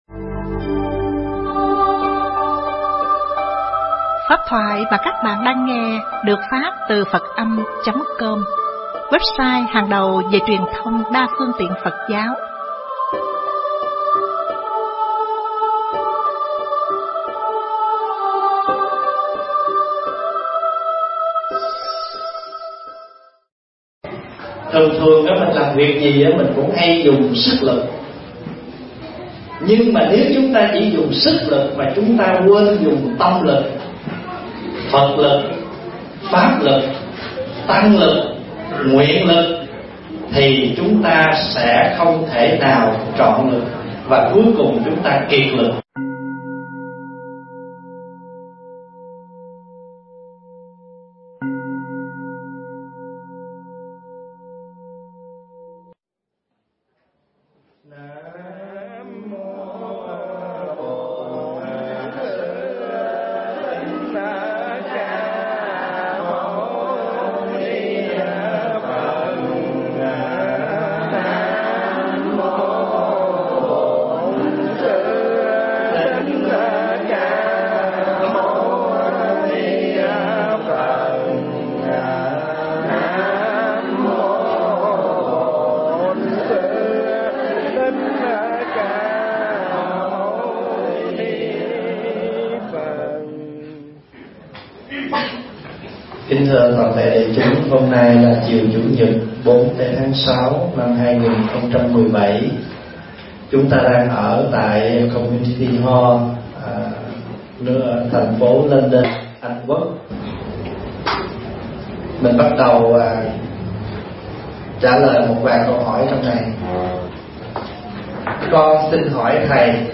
Tâm Lực Và Sức Lực (Vấn Đáp
thuyết giảng tại London, Anh Quốc